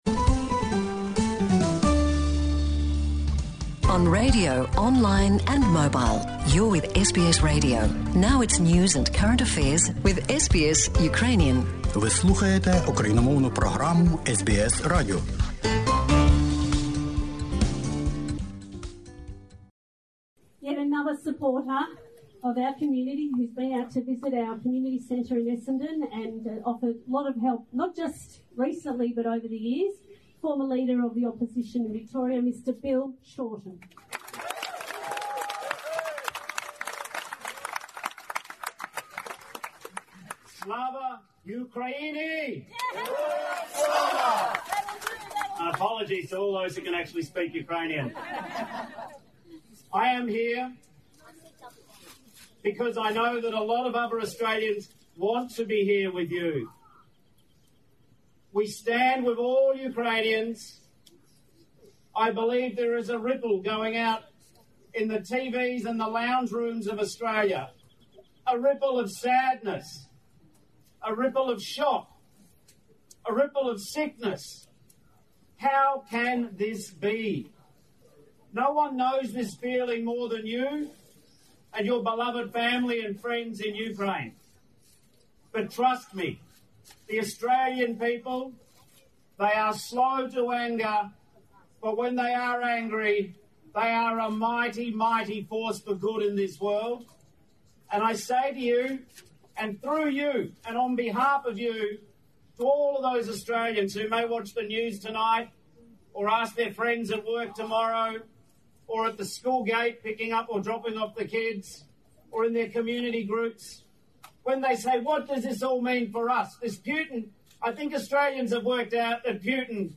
Федеральний парламентар Лейбористської партії Австралії, колишній лідер Федеральної опозиції Австралії Білл Шортен про війну в Україні виступив на мітинґу у час маніфестація у Мельбурні...
The Hon Bill Shorten MP -Anti-war protest in Melbourne against invasion of Ukraine, 27/02/2022.